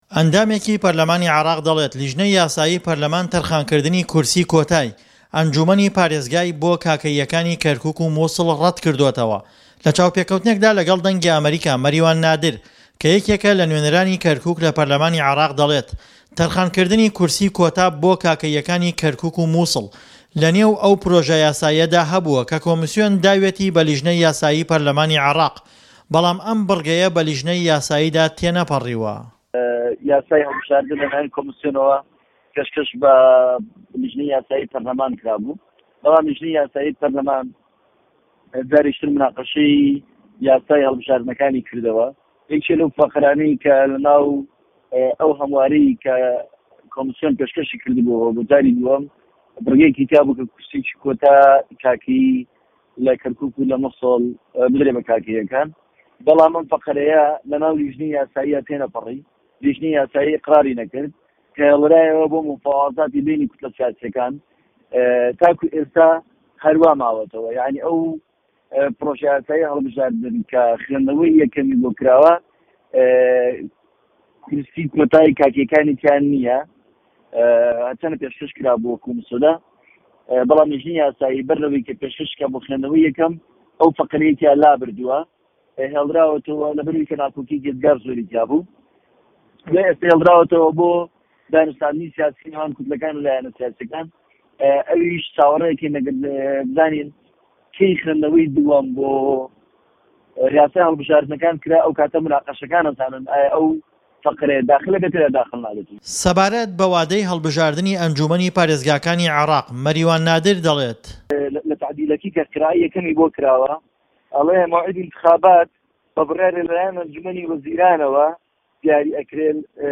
ڕاپۆرت - پاریزگاری كه‌ركووك - كاكه‌ییه‌كان